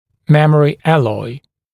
[‘memərɪ ‘ælɔɪ][‘мэмэри ‘элой]сплав с памятью формы